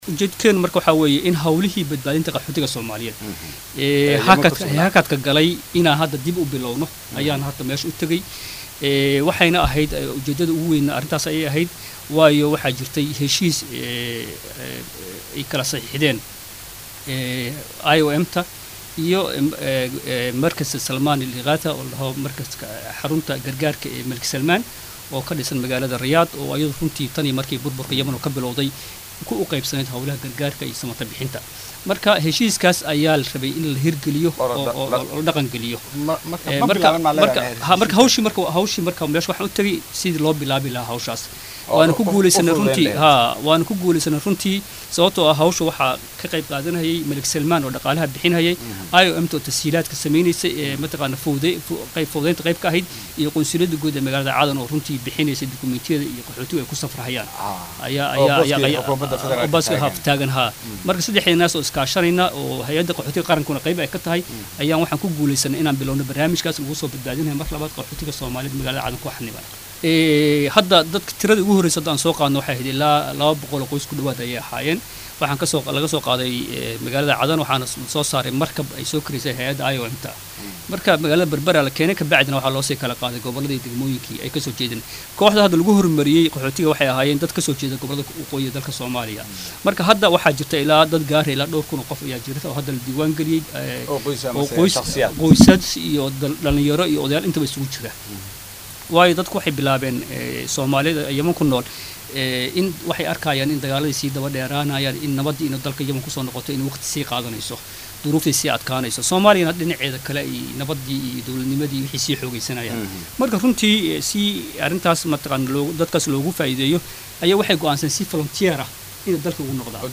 Axmed Cabdi Suudaani oo la hadlay Warbaahinta Qaranka ayaa sheegay in uu bilaabanayo wajiga labaad ee soo daad gureynta muwaadiniinta Soomaaliyeed ay dagaalada ku qabsadeen Yemen, si dalkooda Hooyo dib loogu soo celiyo.